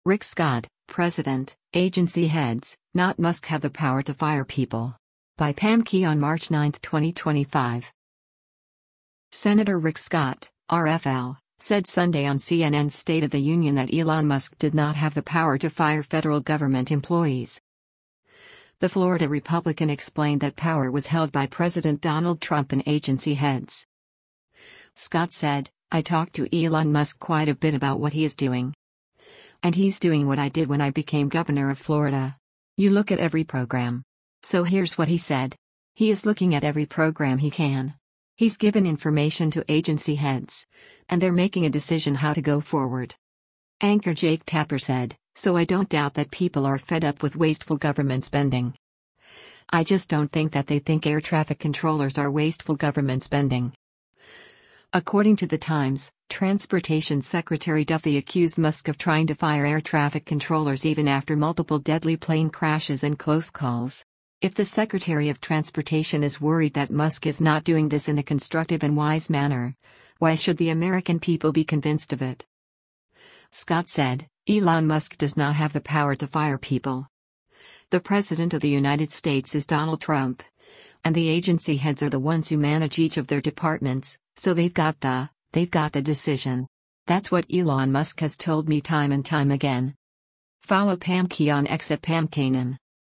Senator Rick Scott (R-FL) said Sunday on CNN’s “State of the Union” that Elon Musk did not “have the power to fire” federal government employees.